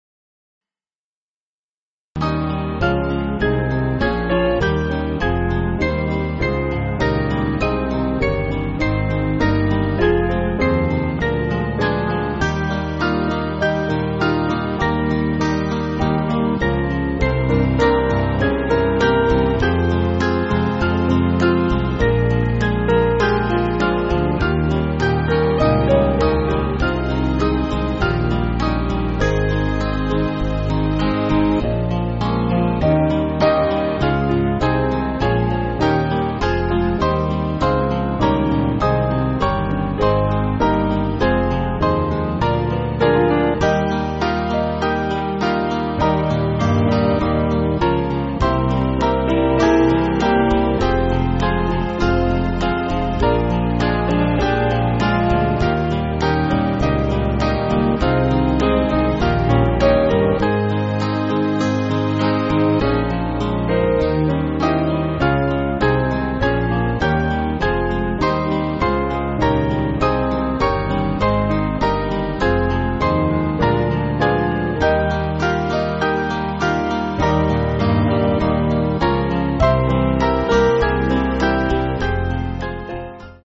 Small Band
(CM)   3/Ab